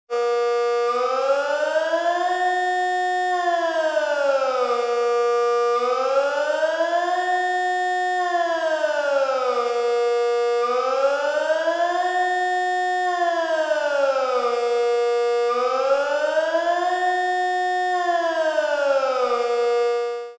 General siren sound
A taster of the general siren.
This is being heard across Switzerland on February 3.